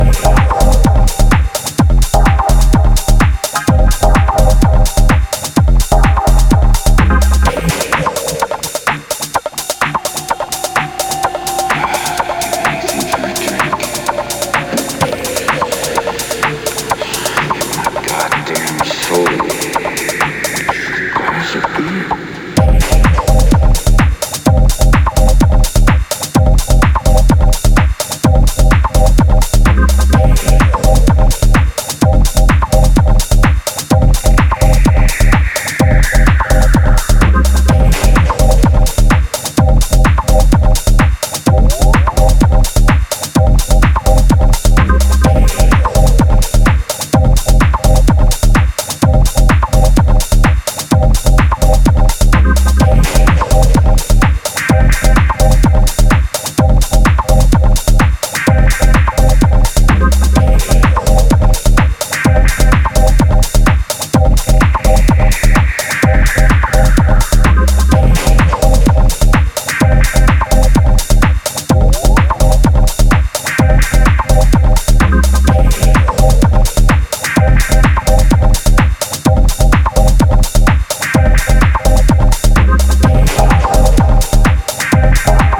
is a techy dancefloor groover with addictive funky bass